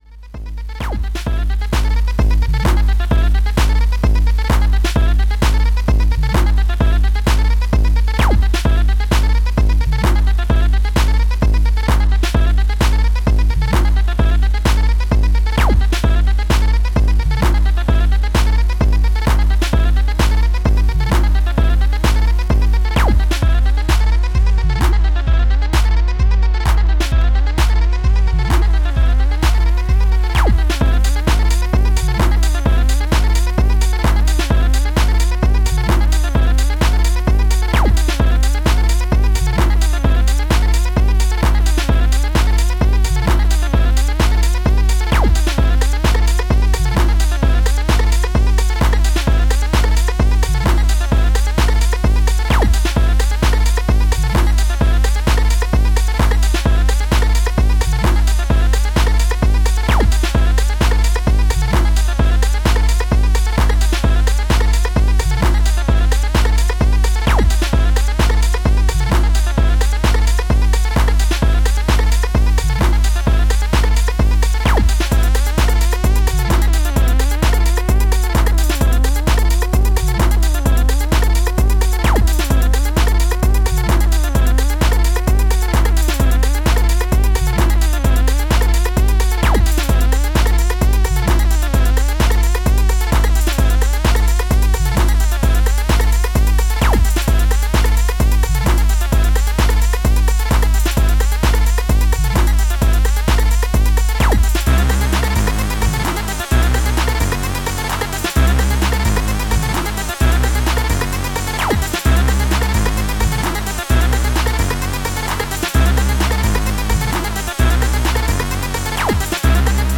シカゴ・スタイルのジャッキン・トラックス！